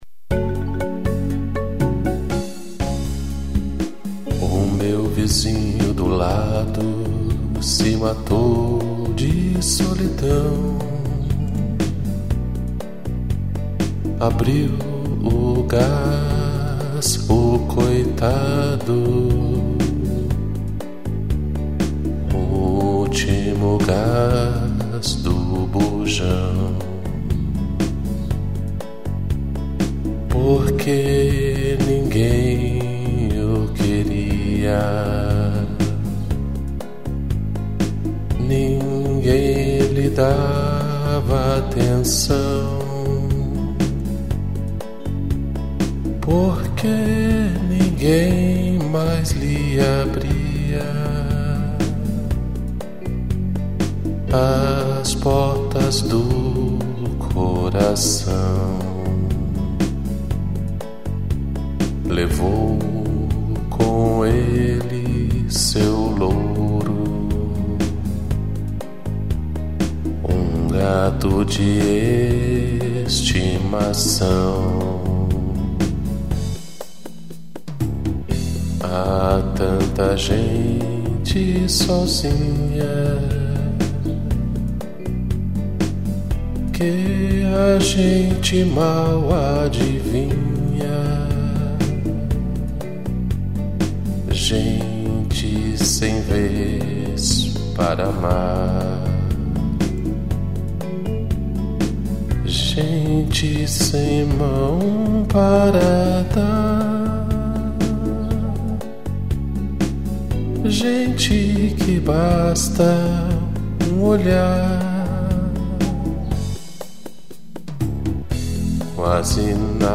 piano, trompete e violino